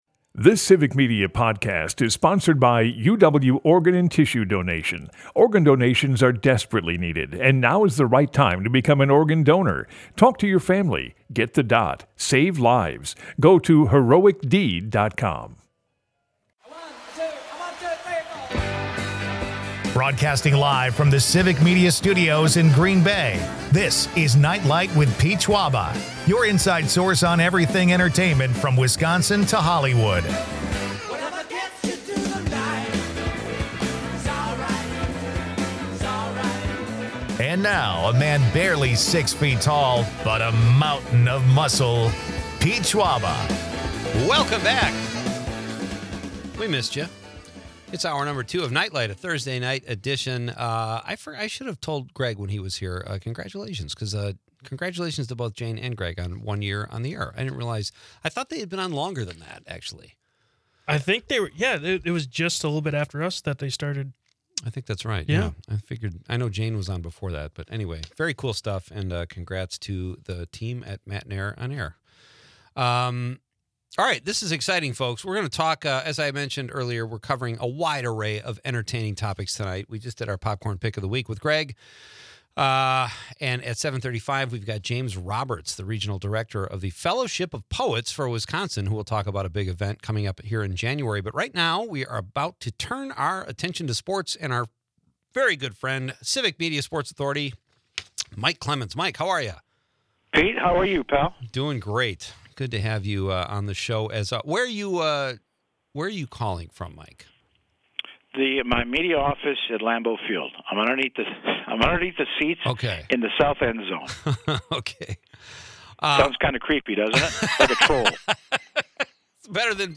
is a part of the Civic Media radio network and airs Monday through Friday from 6-8 pm across Wisconsin.